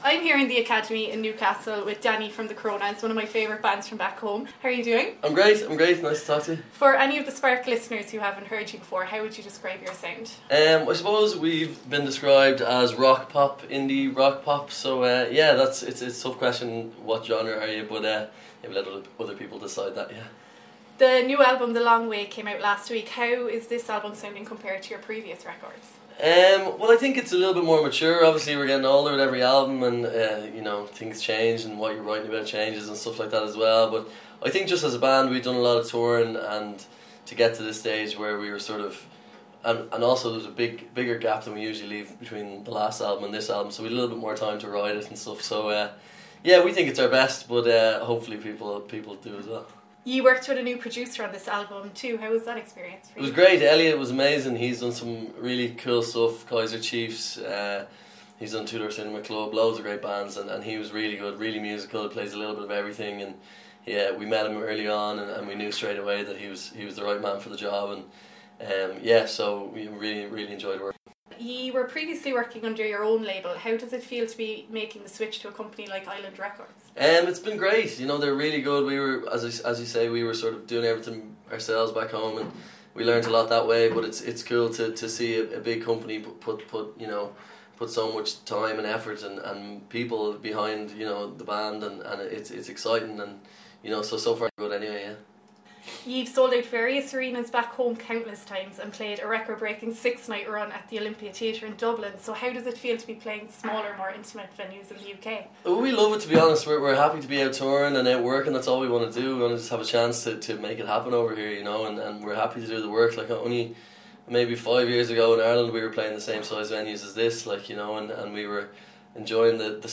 speaks with The Coronas as they play the 02 Academy in Newcastle